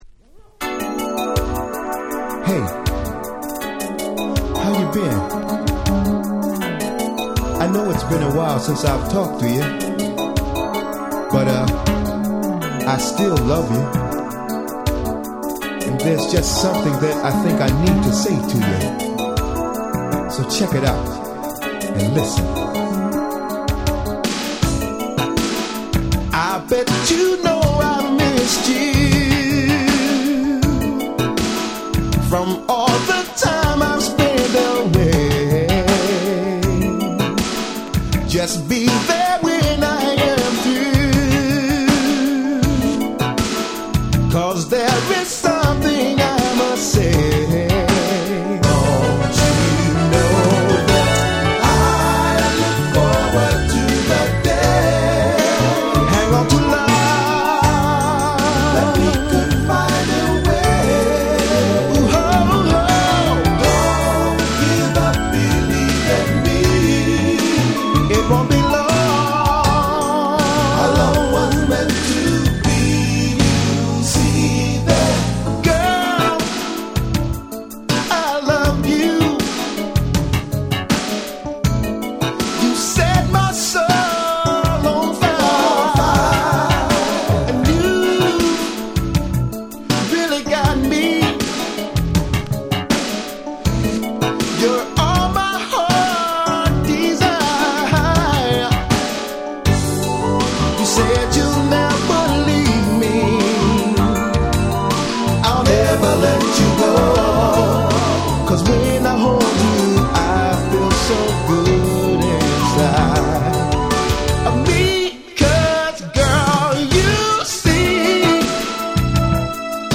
Funkyでハネっハネ！！
90's ハネ系 ニュージャックスウィング スロウジャム バラード